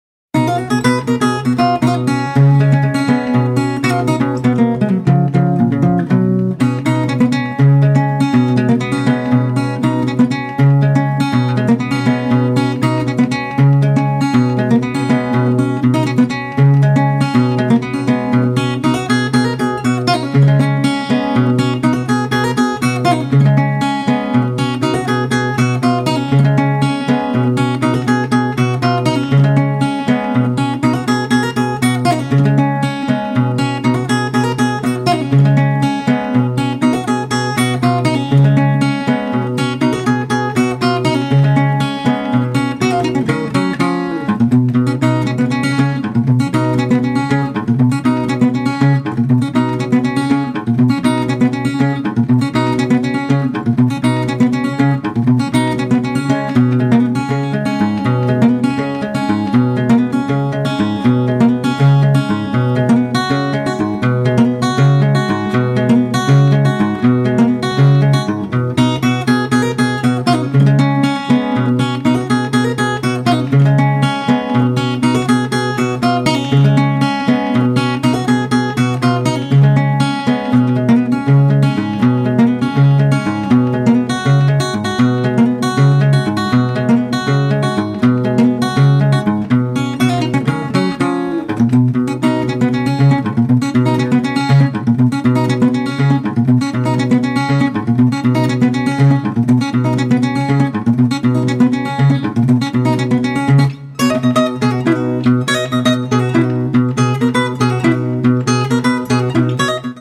ギター演奏で、マダガスカルの音楽スタイルを表現！軽やかでトロピカルな風情を滲ませた好内容！
何となく海の香りも伝わってくる辺りも◎！